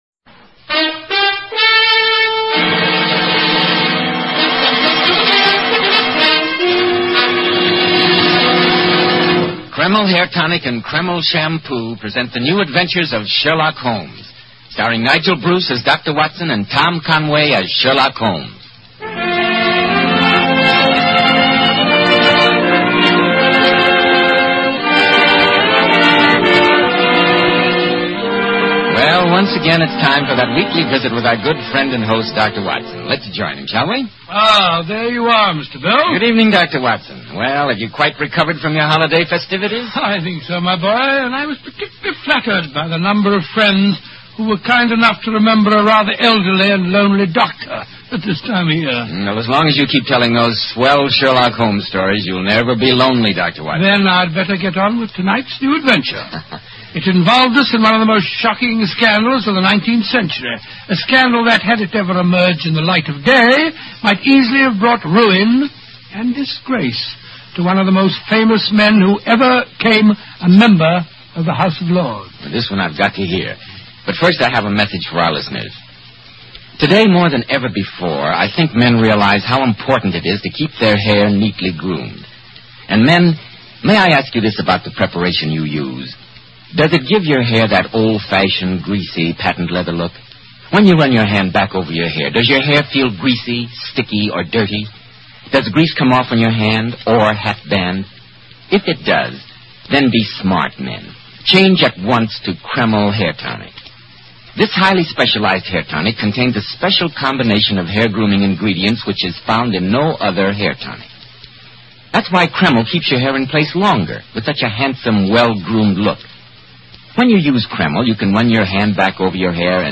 Radio Show Drama with Sherlock Holmes - The Darlington Substitution